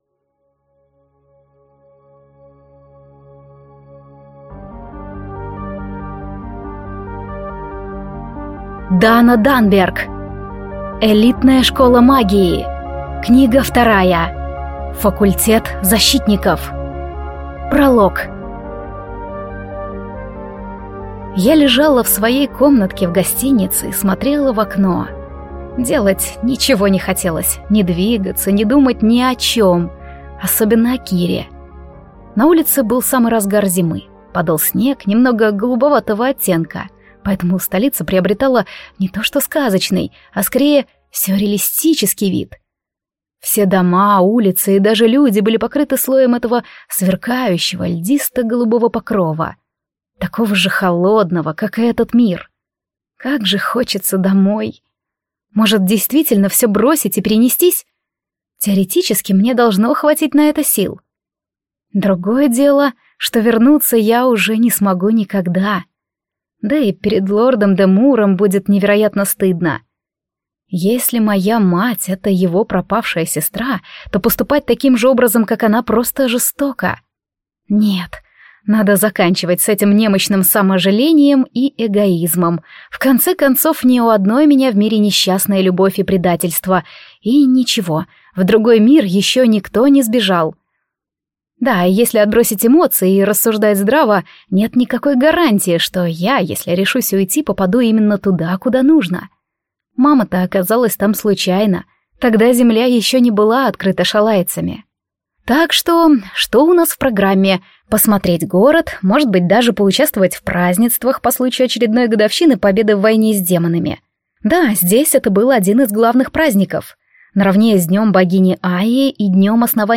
Аудиокнига Элитная школа магии. Книга 2. Факультет Защитников | Библиотека аудиокниг